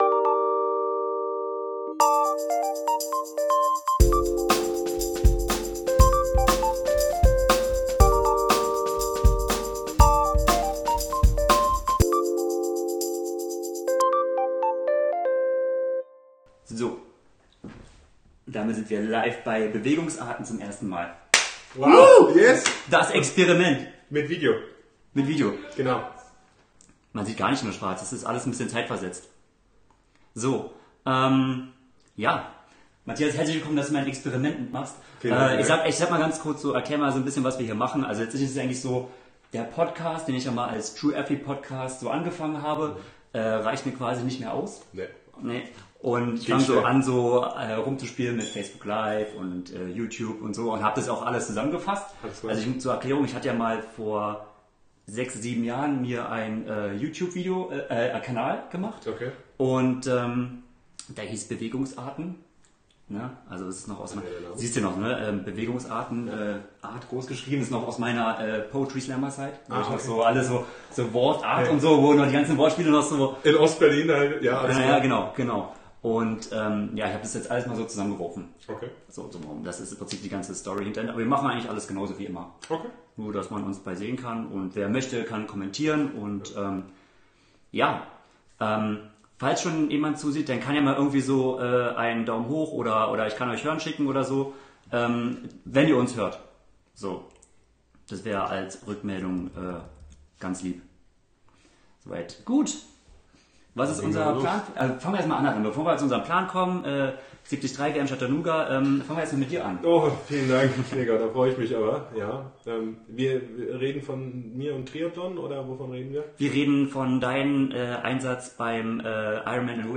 #11 Ironman 70.3 World Championchip Recap (Facebook Livestream) ~ bewegungsARTen Podcast